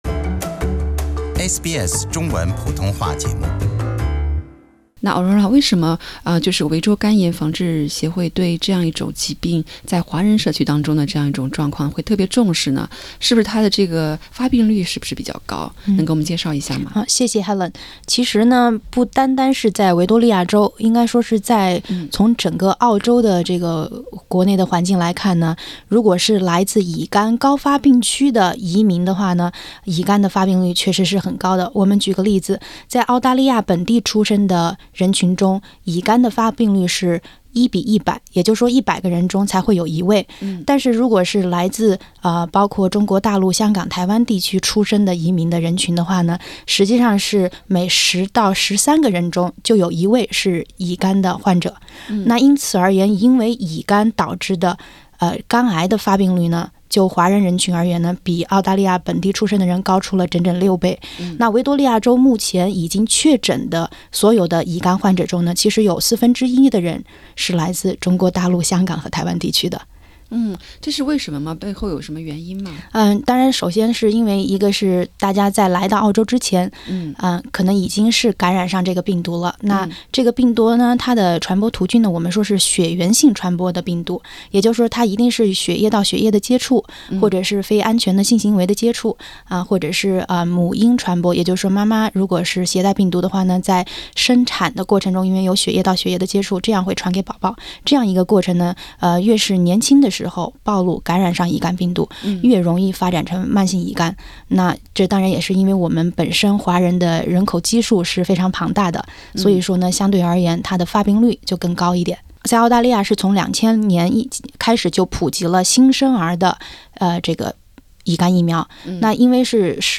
（详细内容可点击上方图片收听采访）